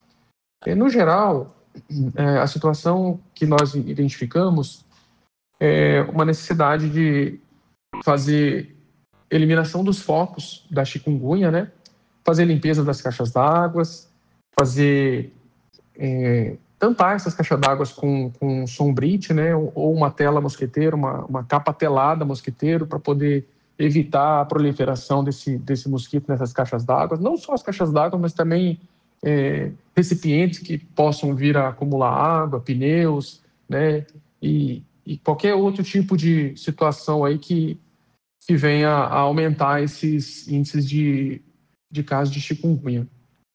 o coordenador adjunto da Defesa Civil estadual, coronel Artêmison Monteiro de Barros, explicou que o apoio foi solicitado pela Secretaria de Saúde para reforçar o enfrentamento às arboviroses, além de garantir o abastecimento de água em comunidades indígenas de Dourados e Itaporã.
SONORA-2-CORONEL-ARTEMISON.mp3